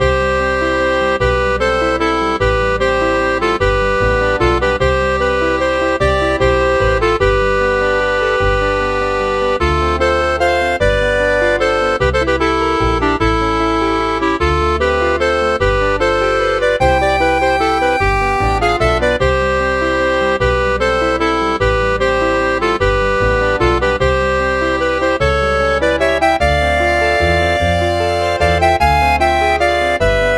Converted from .mid to .ogg
Fair use music sample